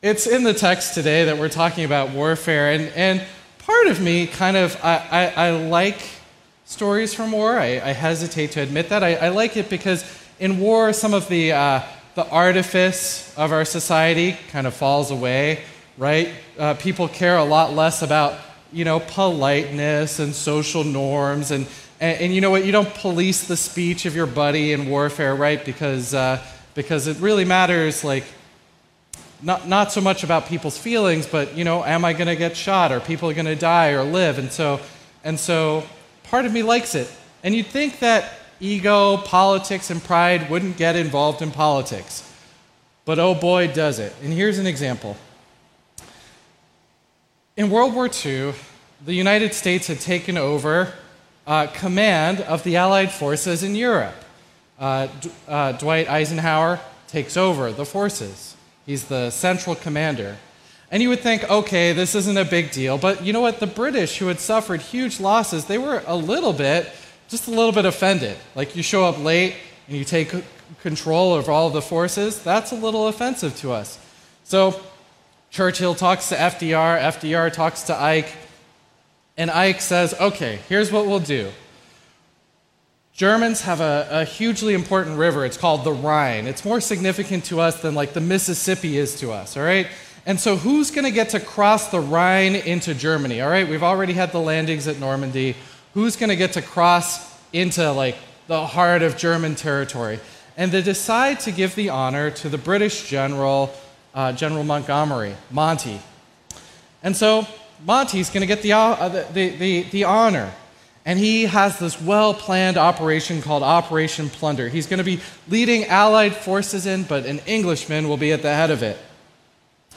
A message from the series "2 Corinthians ."